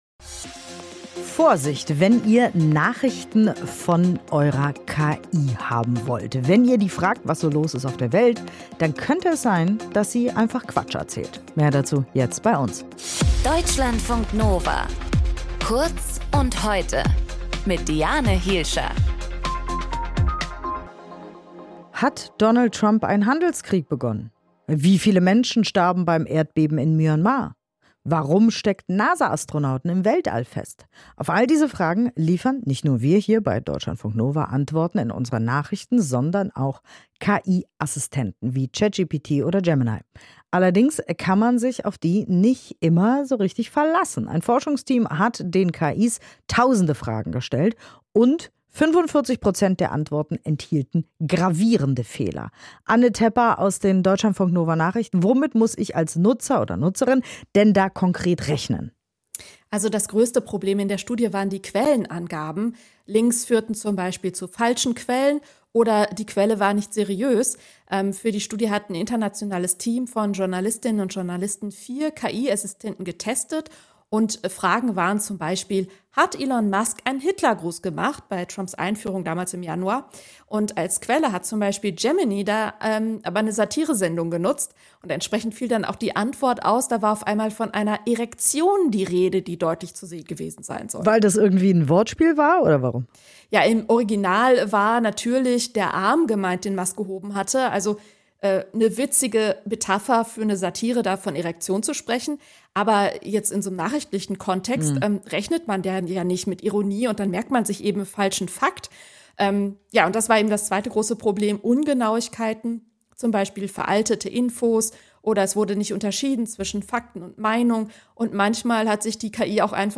In dieser Folge mit:
Moderatorin: